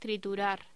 Locución: Triturar